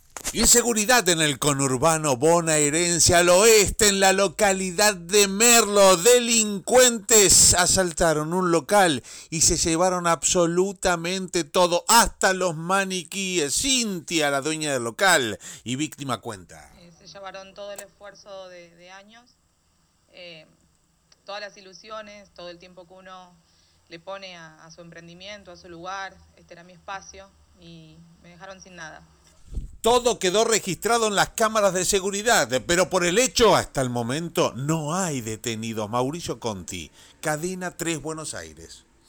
Estamos muy contentos con el inicio de temporada. Tuvimos una ocupación mejor de la esperada, expresó en Cadena 3 Juan Ibarguren.